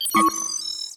UIBeep_Start Transmission.wav